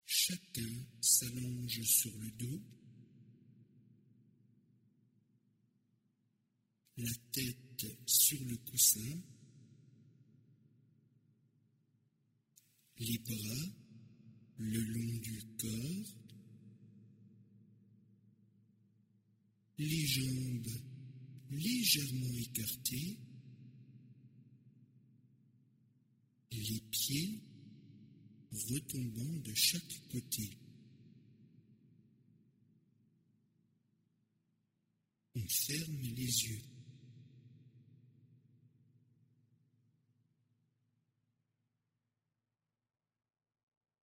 Z2 - Vers le soleil (texte seul)